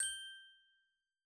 sparkle.wav